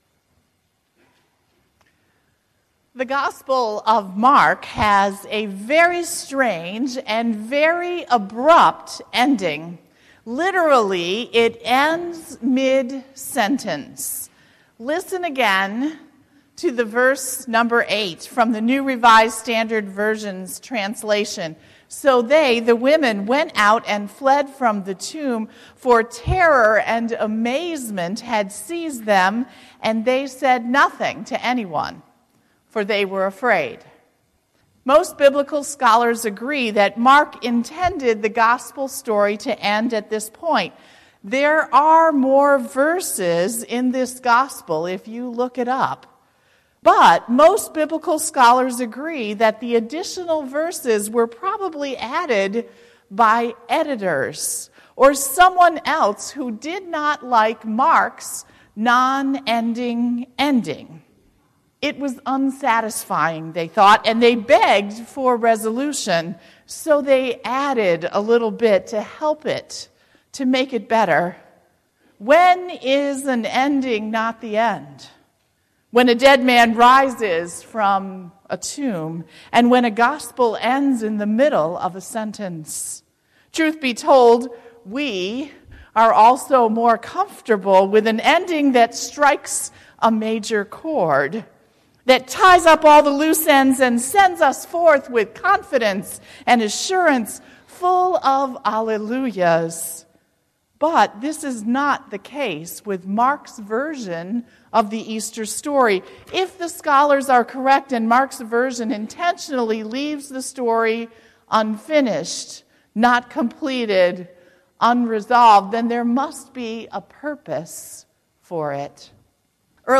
April 1, 2018 Sermon, “Easter Imperfect”
Easter-2018-Sermon.mp3